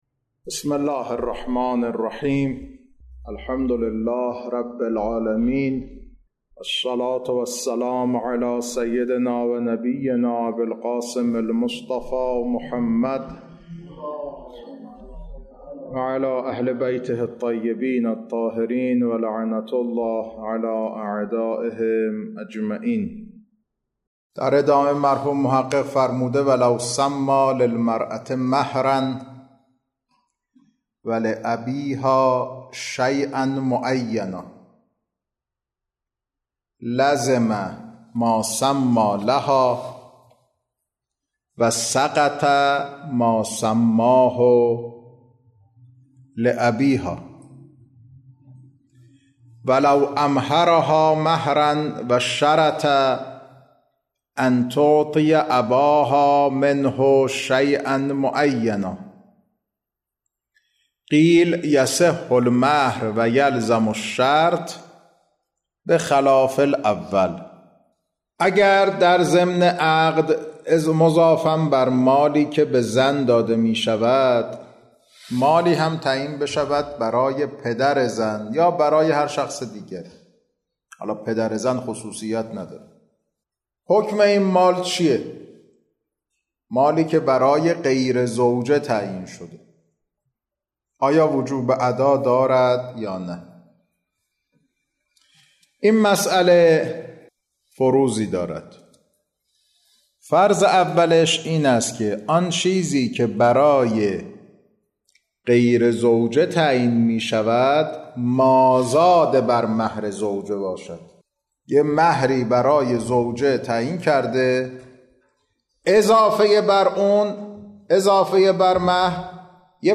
کلاس‌ها خارج فقه